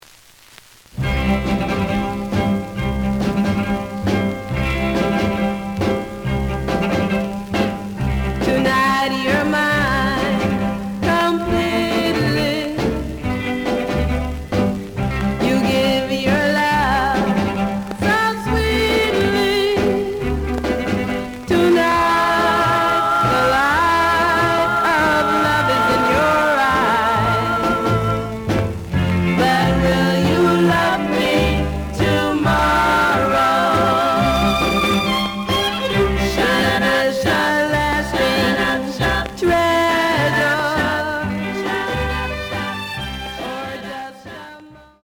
The audio sample is recorded from the actual item.
●Genre: Rhythm And Blues / Rock 'n' Roll
Some click noise on first half of A side due to scratches.